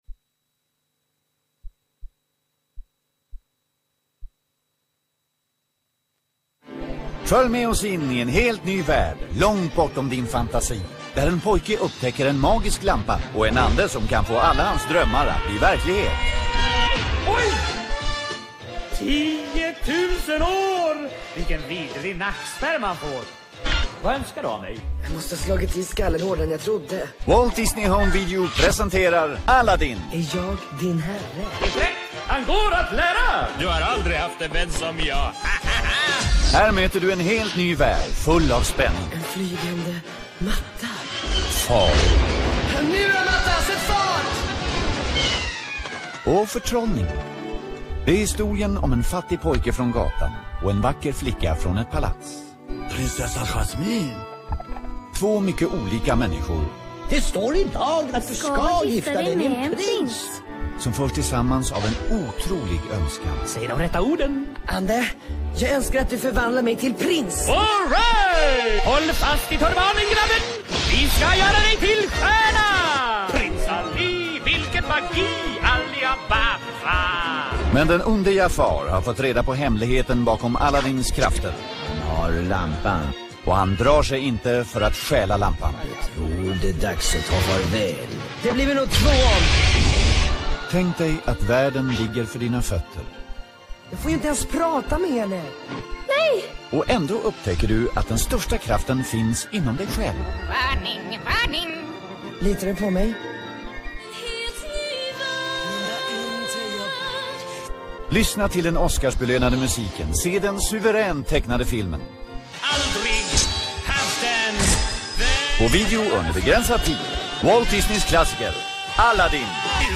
Tecknat Barn Svenska:Aladdin (1992 Walt Disney Home Video AB) VHSRIPPEN (Svenska) Trailer (HD)